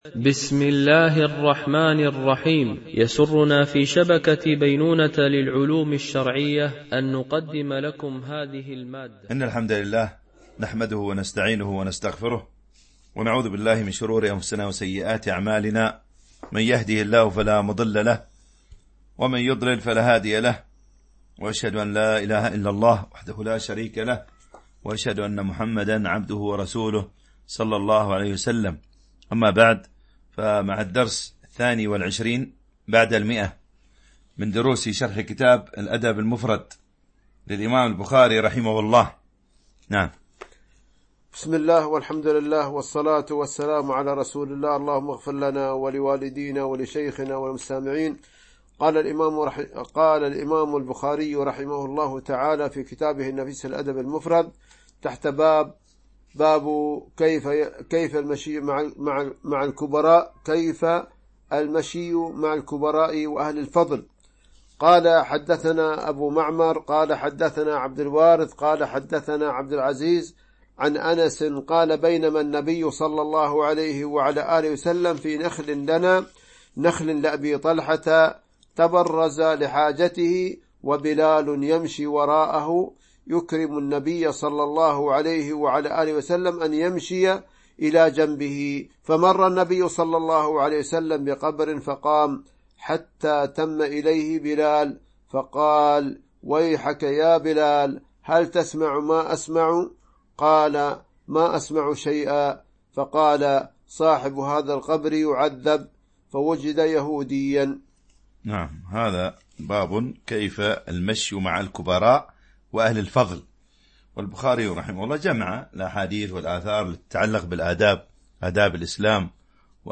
شرح الأدب المفرد للبخاري ـ الدرس 122 ( الحديث 853- 863 )
MP3 Mono 22kHz 32Kbps (CBR)